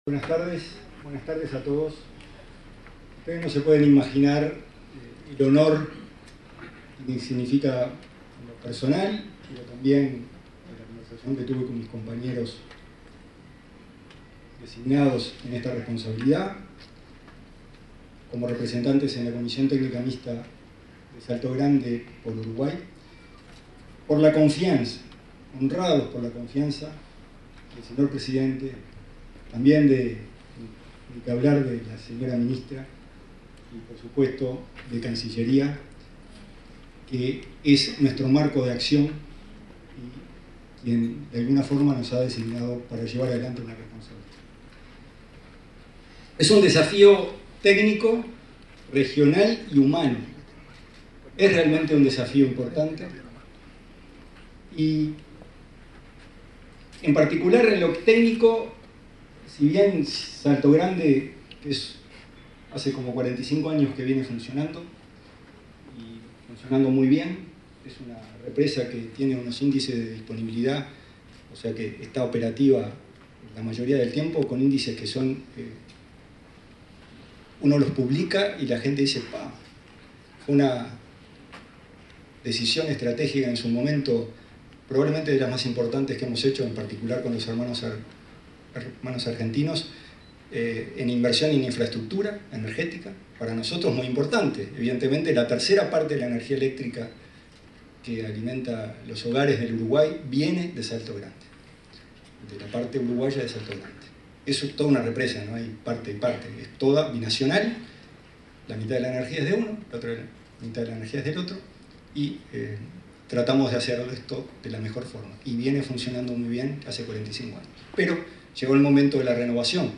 Palabras del presidente de la CTM, Gonzalo Casaravilla
Palabras del presidente de la CTM, Gonzalo Casaravilla 08/05/2025 Compartir Facebook X Copiar enlace WhatsApp LinkedIn Este jueves 8 asumieron las nuevas autoridades de la Comisión Técnica Mixta de Salto Grande (CTM). Su nuevo presidente, Gonzalo Casaravilla, se expresó durante la ceremonia.